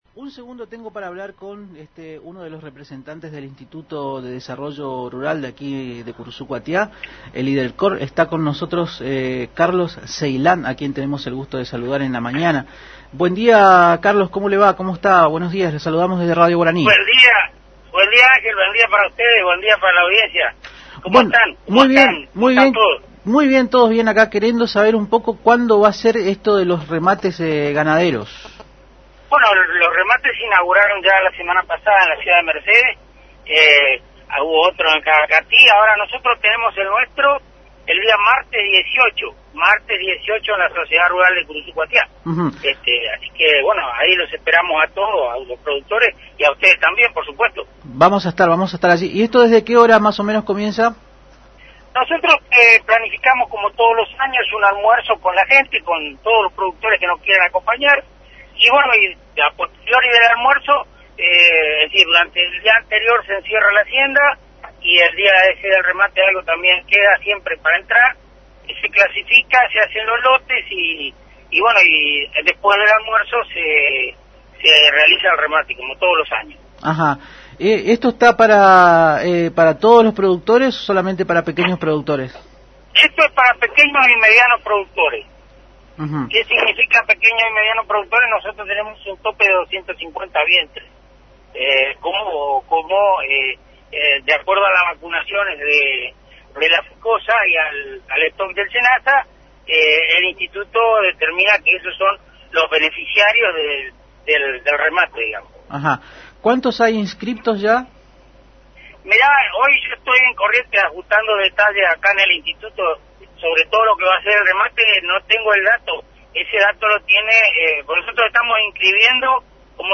Según mencionó en una entrevista realizada en Arriba Ciudad a través de la AM970 Radio Guarani, el día anterior se cierra la hacienda, y al día siguiente se clasifica y se realiza, como todos los años, el tradicional almuerzo y luego viene el remate en sí mismo.